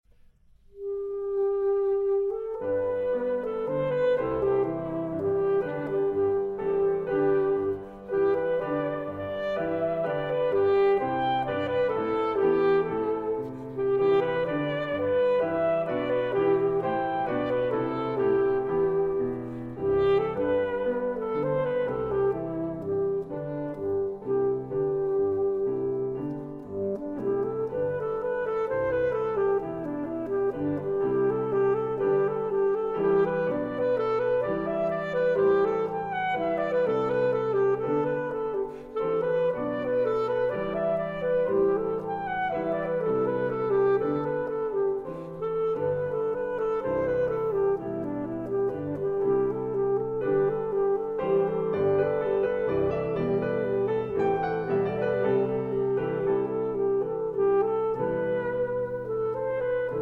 saxophone
piano